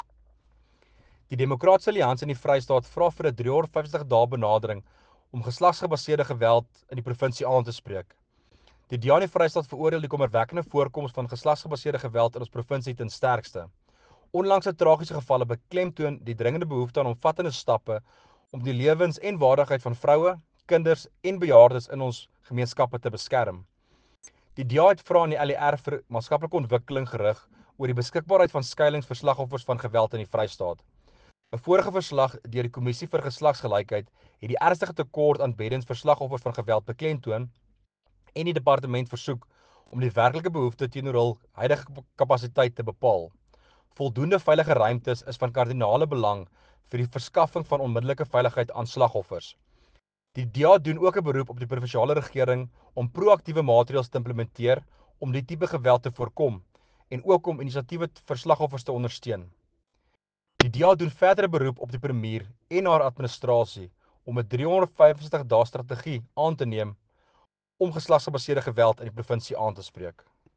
Issued by Werner Pretorius – DA Member of the Free State Provincial Legislature
Afrikaans soundbites by Werner Pretorius MPL